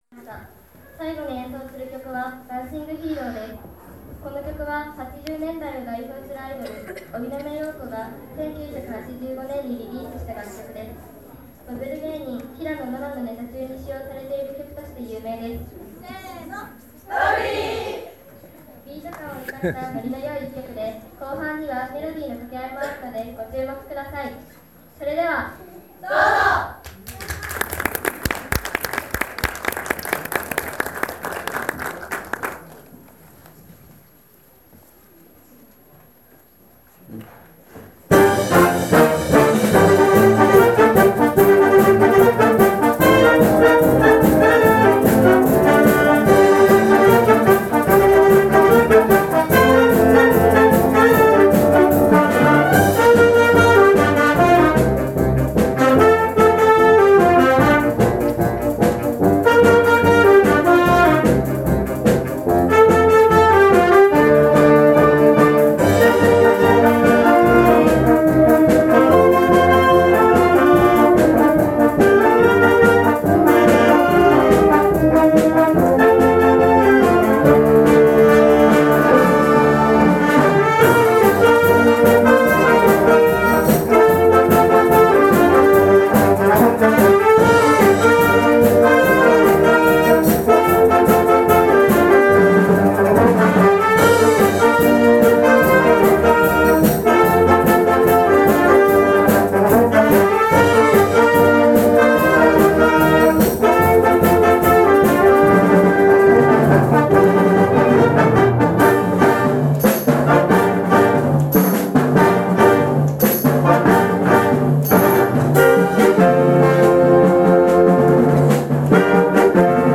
春光台地区センターまつりでの演奏！
７月１日（日），春光台地区センター祭りが行われました。
その中で，高台小学校の素敵な演奏に続き，本校の吹奏楽部が中学生らしいすばらしい演奏を披露していました。